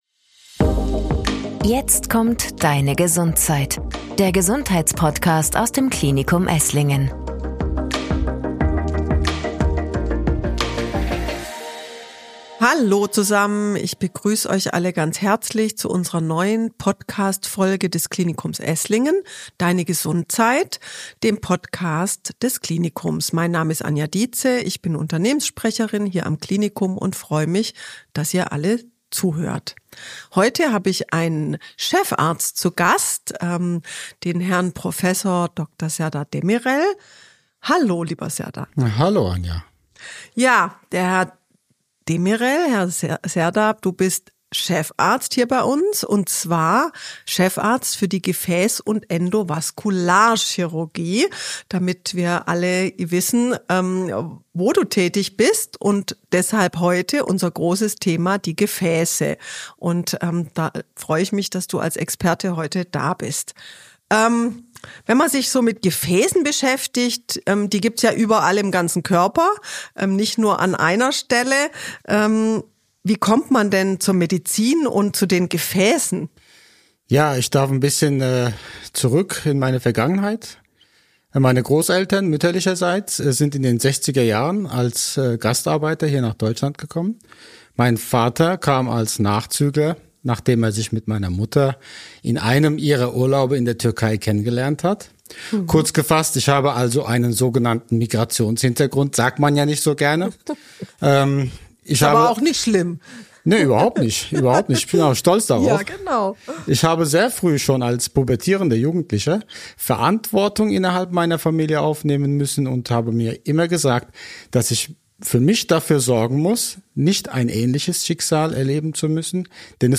Warum Deine Gefäße mehr Aufmerksamkeit verdienen - ein aufschlussreiches Gespräch